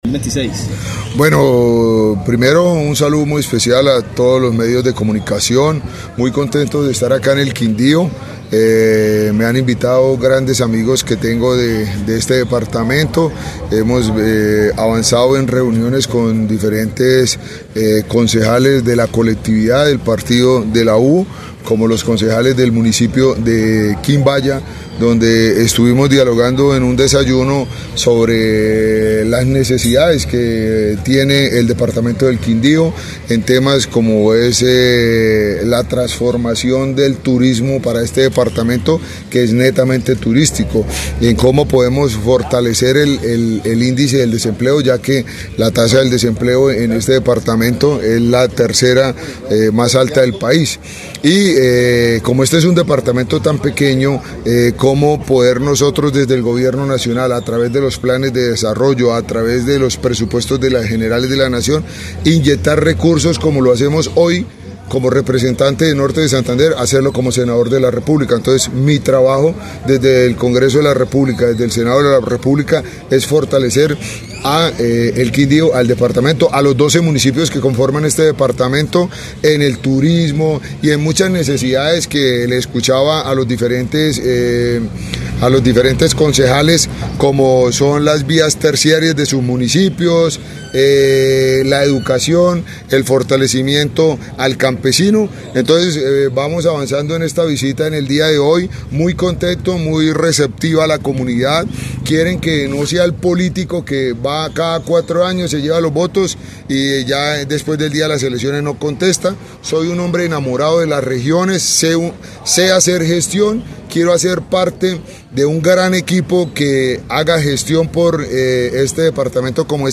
Wilmer Ramiro Carrillo, candidato al senado por el partido de la U